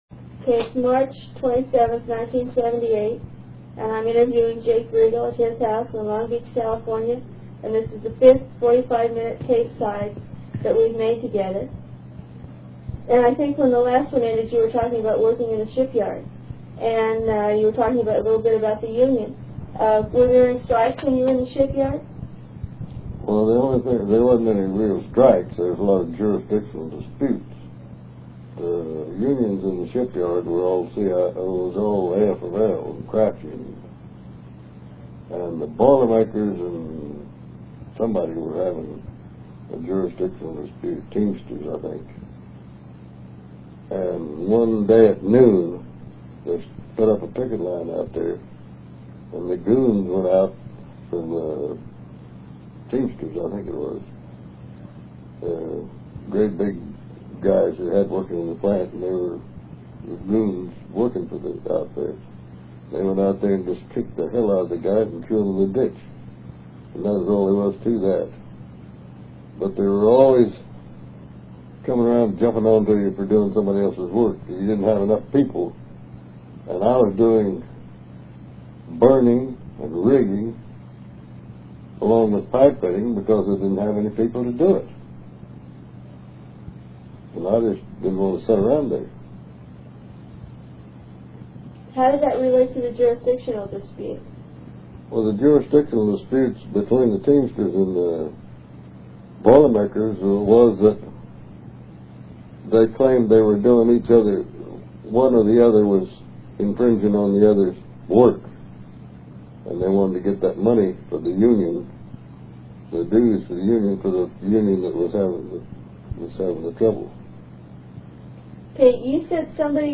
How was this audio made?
was interviewed in his home